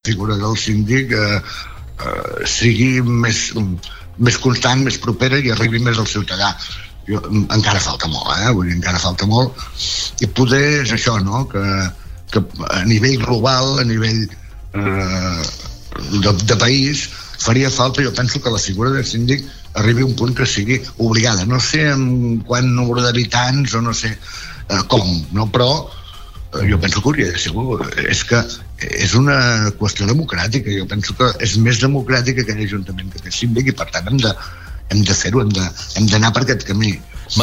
El Síndic de Greuges de Sant Feliu de Guíxols va atendre 200 casos l’any 2016, segons ha explicat el síndic Josep Rius a una entrevista a Ràdio Capital l’Empordà, reproduint el balanç que va fer en el ple municipal del seu primer any després de prendre possessió del càrrec.